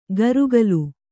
♪ garugalu